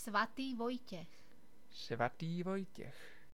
Vojtěch (Czech pronunciation:
The proper Czech spelling of the name is 'Vojtěch', pronounced [ˈvojcɛx].
Cs-svaty_Vojtech.ogg.mp3